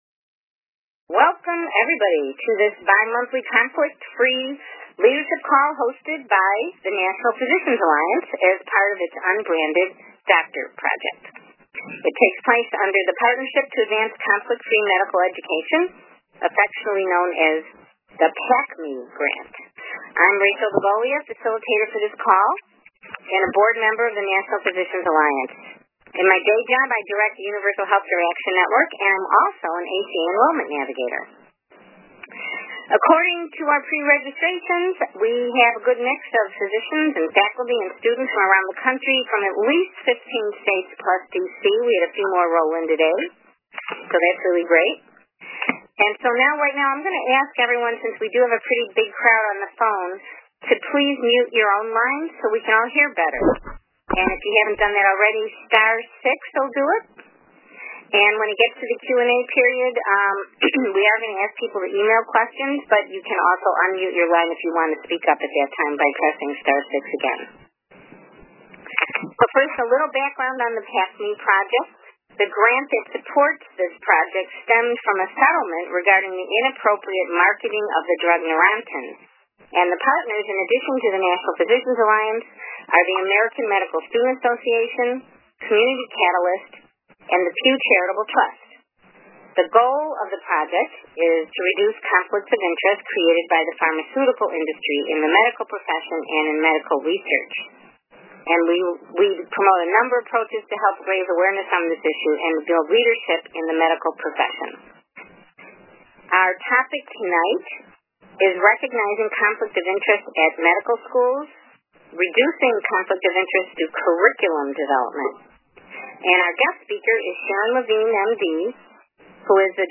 This lecture series highlights advocacy and policy experts who provide technical assistance and leadership development for physicians, residents, and medical students interested in conflict-of-interest reform efforts.
Call Recording Click to listen or download (1:00:40)